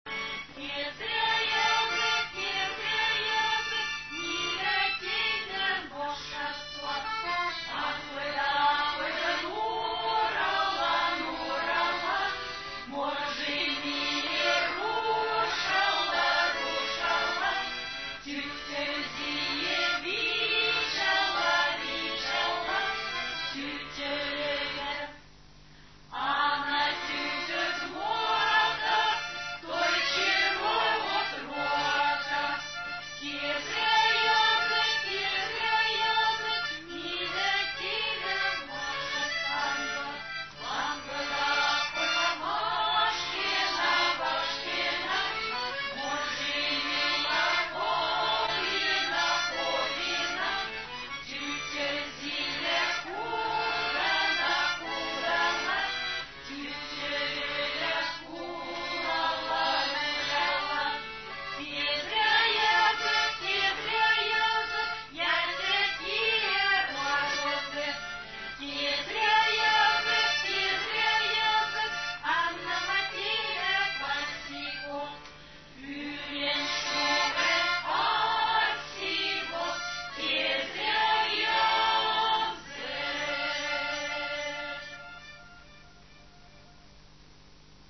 Народная песня "Пряхи"
куплеты песни звучат в исполнении фольклорного коллектива автономии тверских карел "Карельская березка". Видеоряд монтирован из видеозаписи выступления хора на Тверском телевидении.